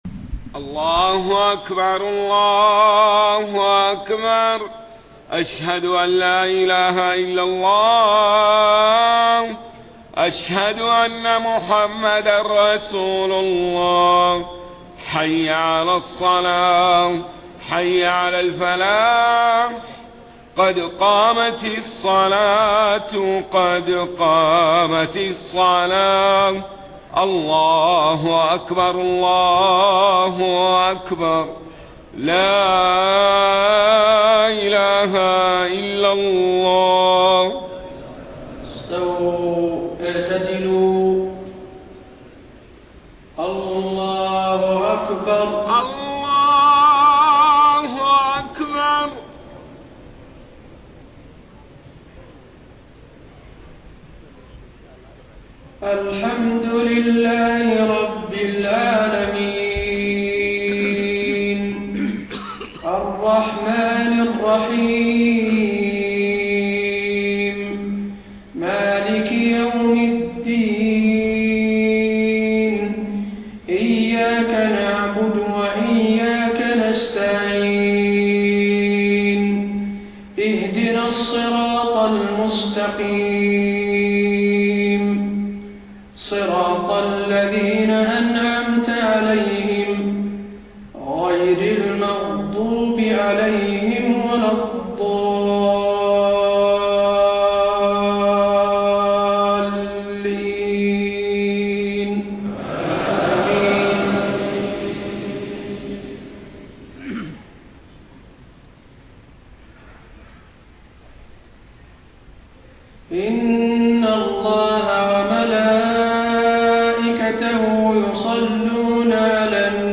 صلاة المغرب 5 ربيع الأول 1431هـ من سورة الأحزاب 56-62 و الكوثر > 1431 🕌 > الفروض - تلاوات الحرمين